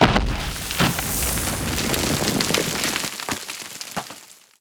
Divergent / mods / JSRS Sound Mod / gamedata / sounds / weapons / _boom / mono / dirt5.ogg
dirt5.ogg